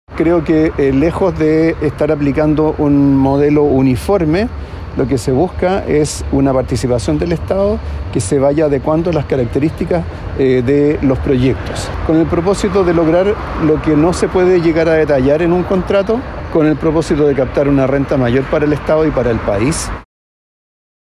Así lo explicó el ministro Marcel.